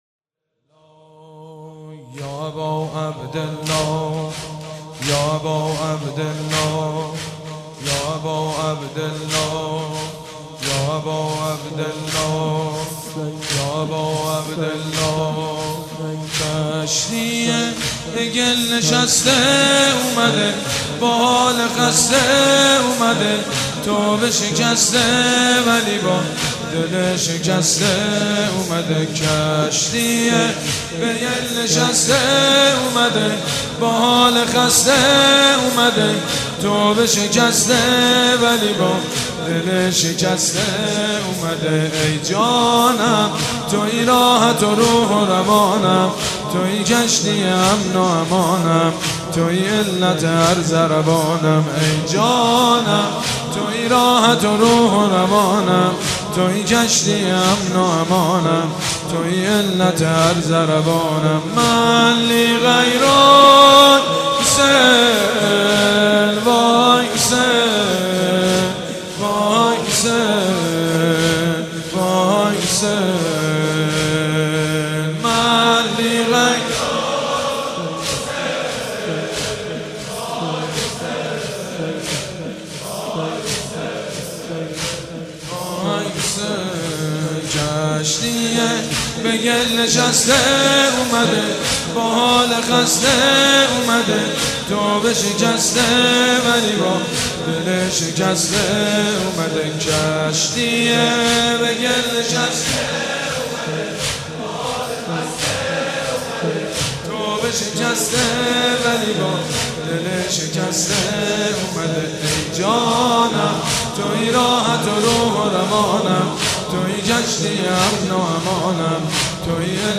روضه
روضه سیدمجید بنی‌فاطمه